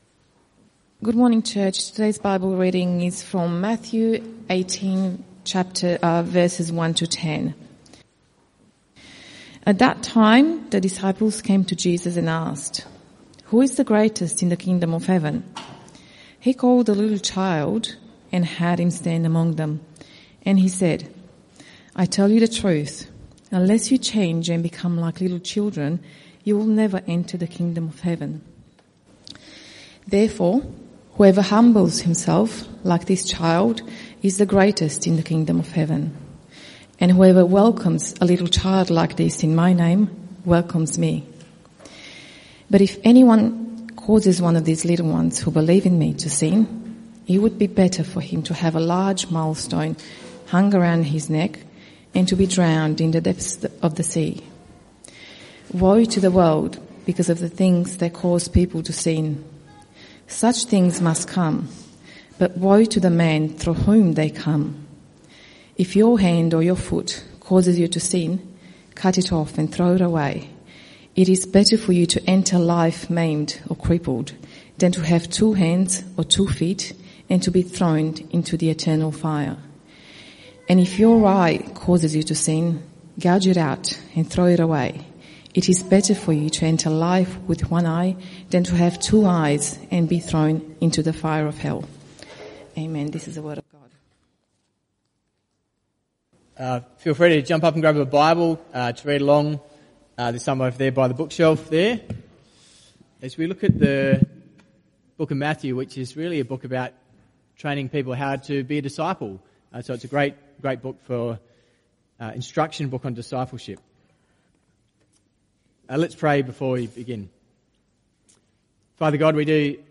CBC Service: 24 November 2024 Series
Type: Sermons